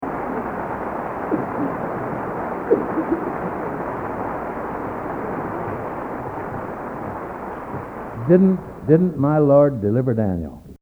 Collection: End of Season, 1971
Genre: | Type: Director intros, emceeing